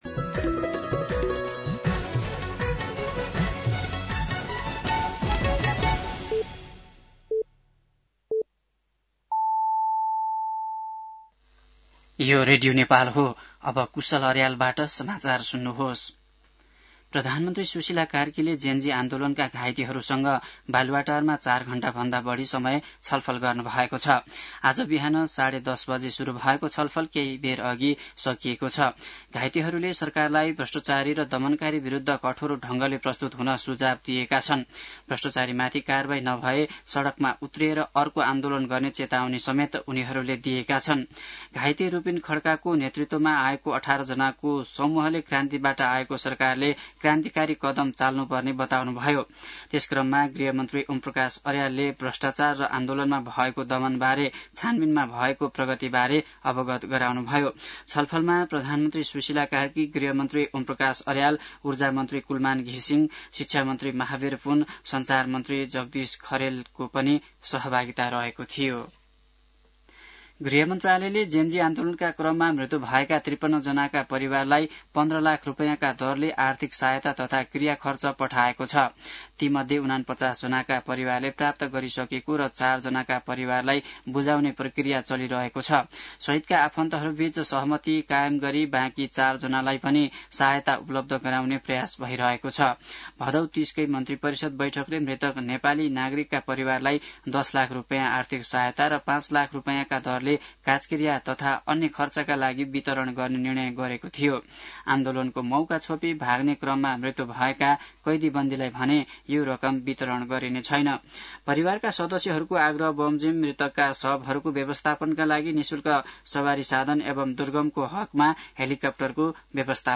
दिउँसो ४ बजेको नेपाली समाचार : ४ कार्तिक , २०८२
4-pm-Nepali-News-10.mp3